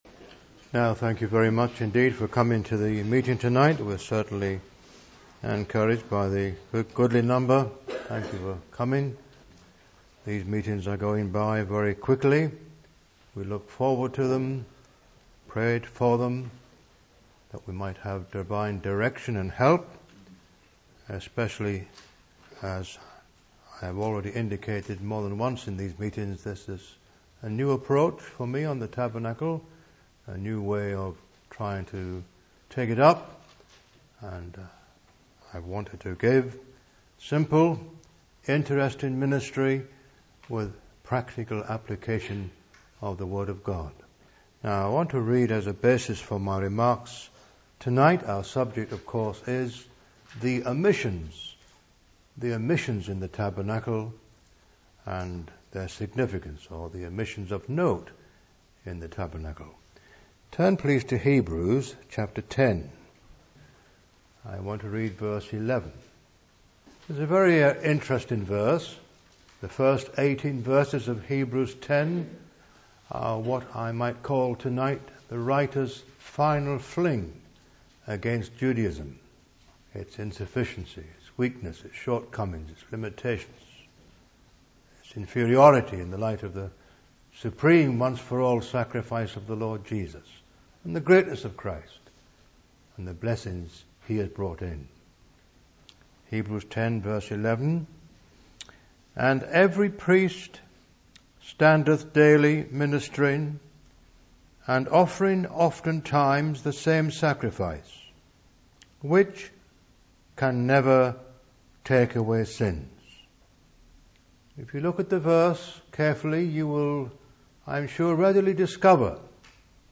(Message preached 3rd Dec 2009)